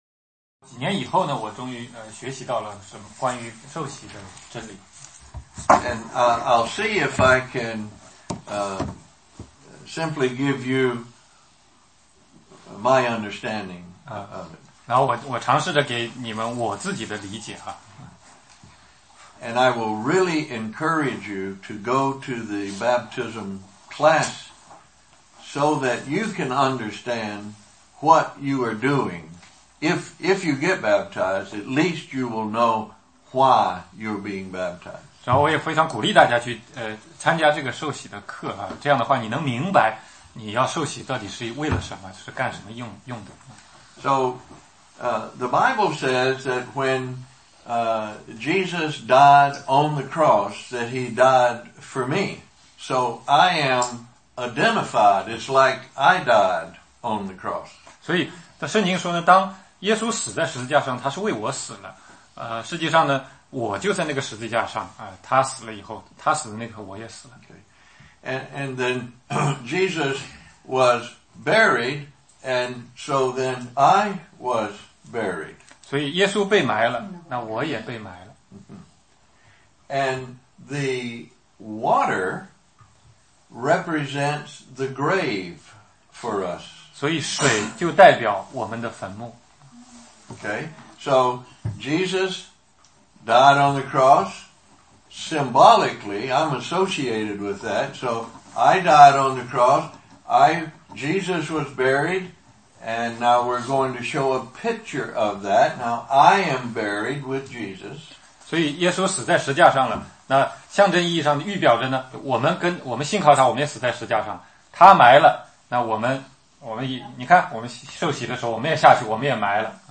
16街讲道录音 - 历代志下 20 29-36